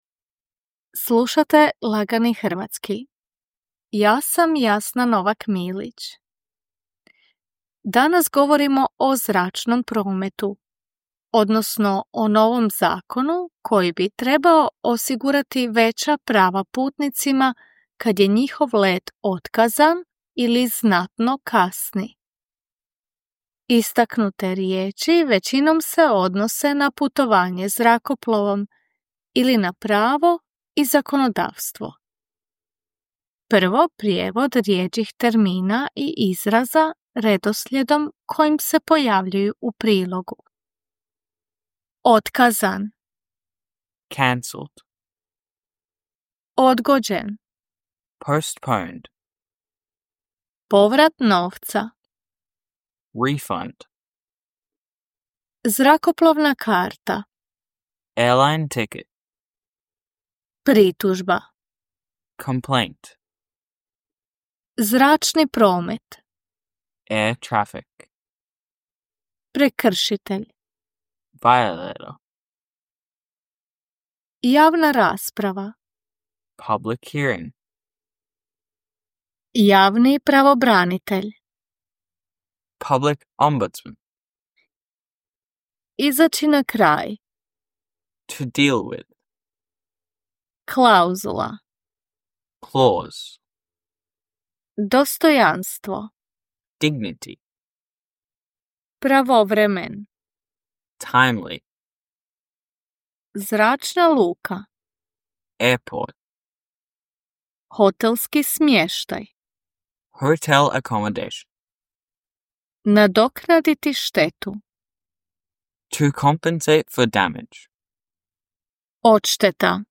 Before we move on to the feature, you will hear some of the more complex vocabulary and expressions, followed by their English translations.
“Easy Croatian” is intended for those learning or wanting to brush up on their Croatian. News is written in simpler and shorter sentences and read at a slower pace.…